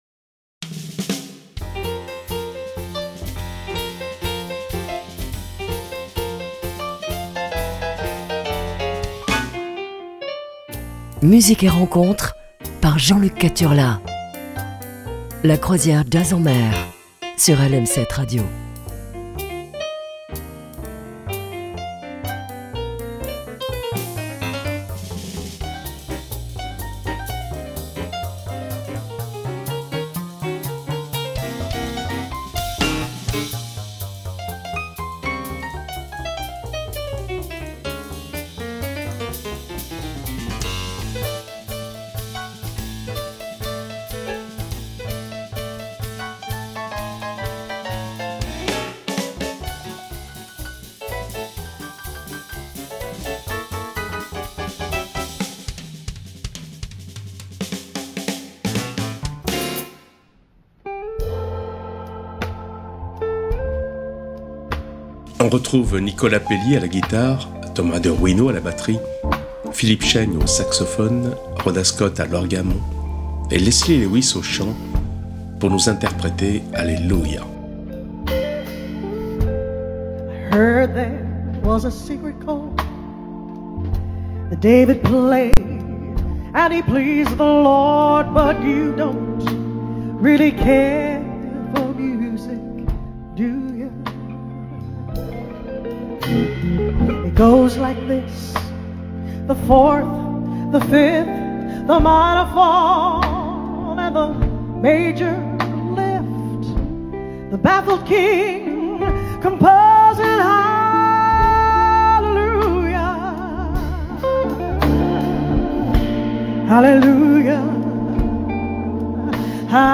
passer un quart d'heure sur des rythmes jazzy